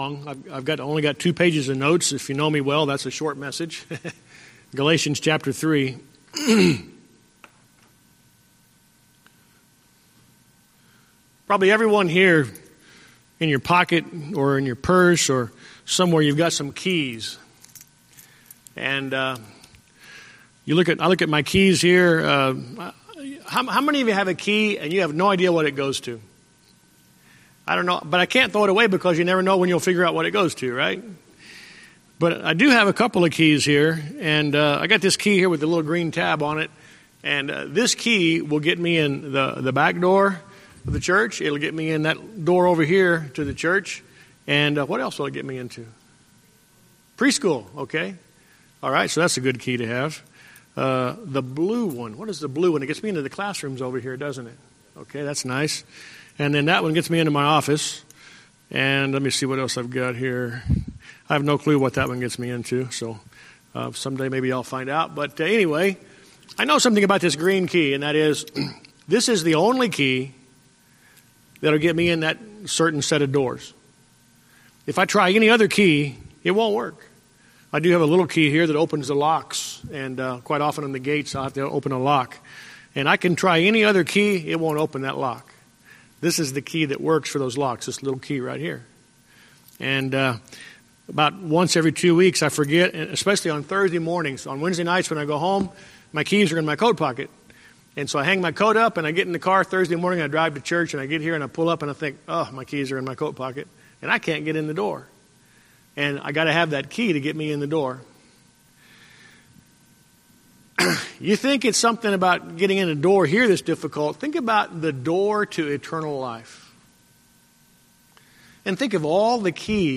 Sermon Recordings